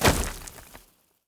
Tree Hit
Tree Explosion.wav